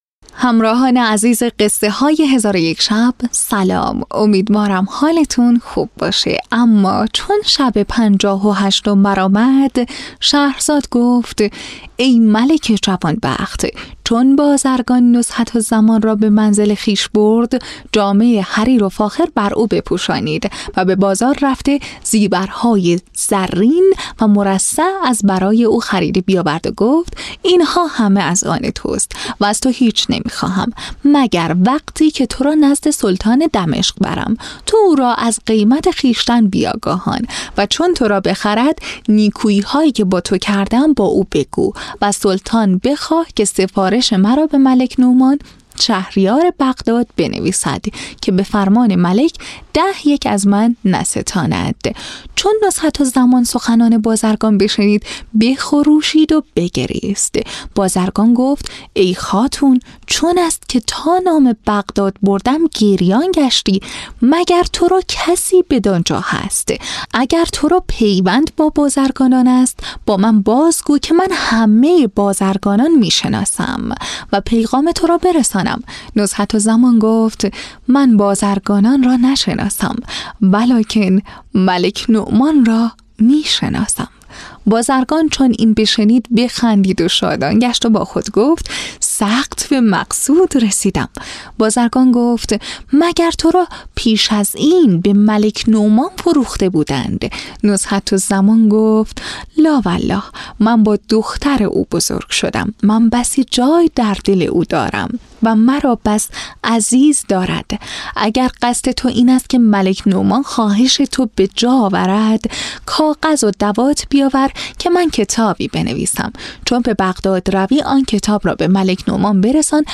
تهیه شده در استودیو نت به نت